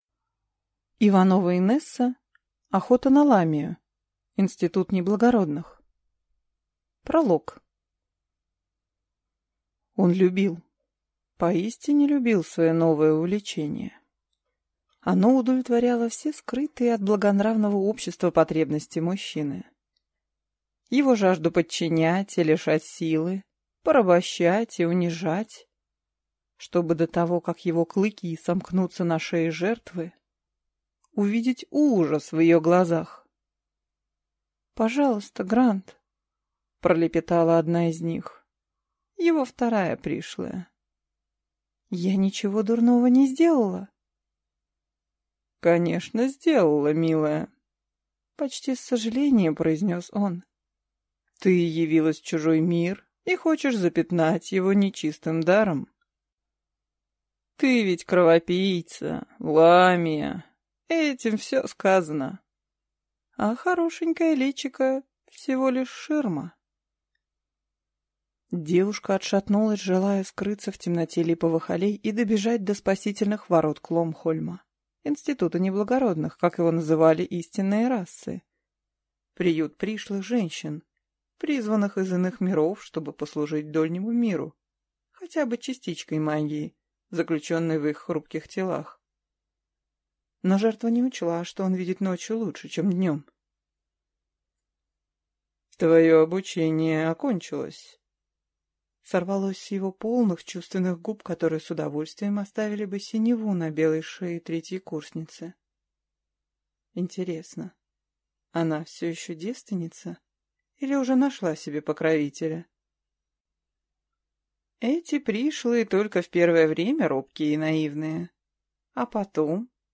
Аудиокнига Охота на ламию. Институт неблагородных | Библиотека аудиокниг
Прослушать и бесплатно скачать фрагмент аудиокниги